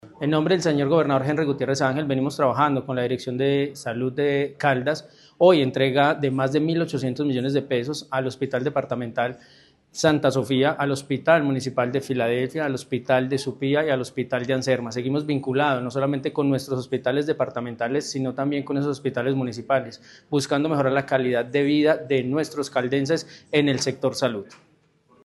Ronald Bonilla, Secretario Privado de la Gobernación de Caldas.